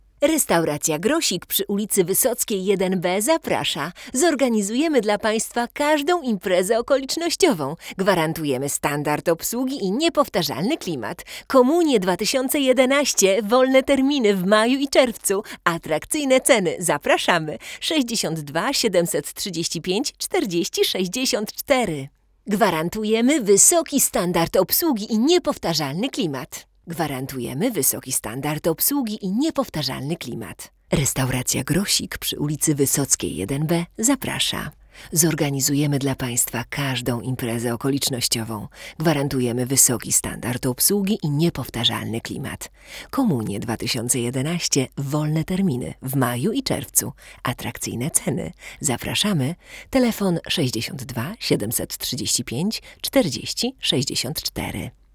Telefunken AK 47 + Pre-73
W naszym teście mikrofon ten mocno plusował piękną, elegancką barwą, z której go Pre-73 mówiąc wprost - wykastrował. Brzmienie środka jest chyba najpełniejsze, Telefunken jest najbardziej dźwięczny, ale podbicie na wysokich tonach irytujące dużo bardziej niż u pozostałych modeli.